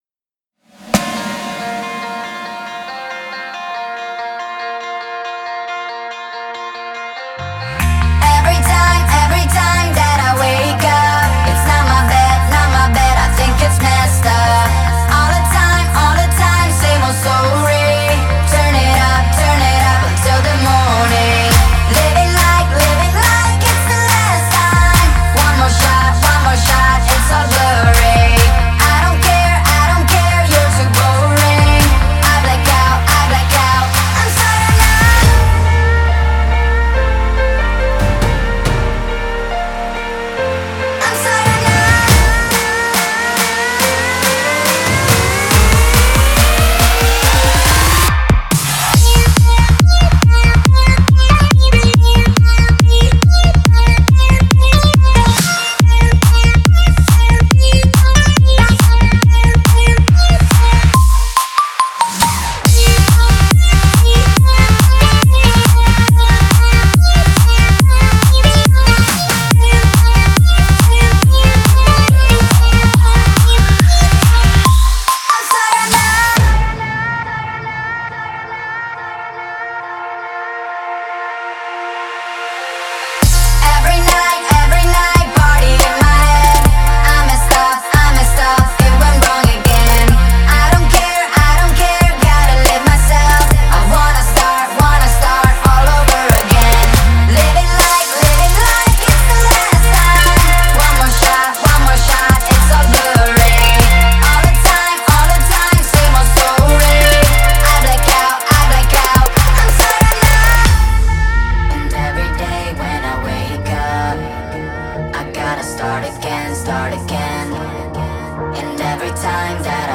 это энергичная танцевальная композиция в жанре EDM